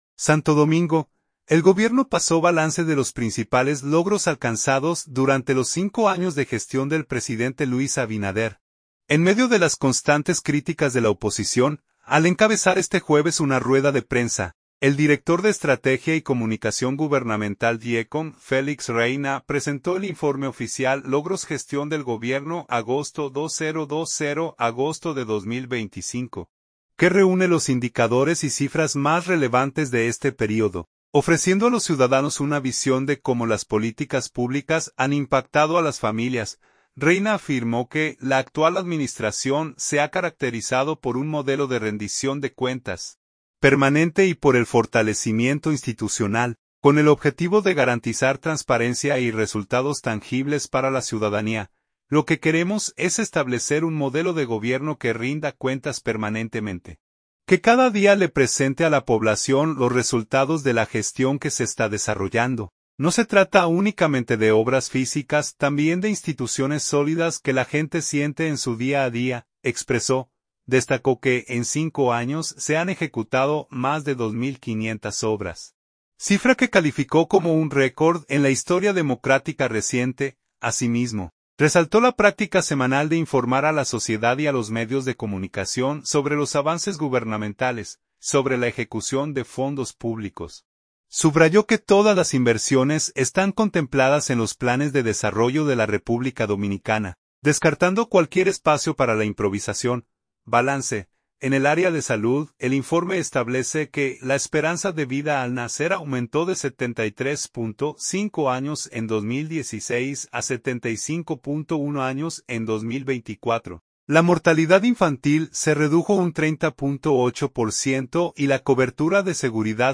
Al encabezar este jueves una rueda de prensa, el director de Estrategia y Comunicación Gubernamental (DIECOM), Félix Reyna, presentó el informe oficial “Logros Gestión del Gobierno Agosto 2020 – Agosto 2025”, que reúne los indicadores y cifras más relevantes de este período, ofreciendo a los ciudadanos una visión de cómo las políticas públicas han impactado a las familias.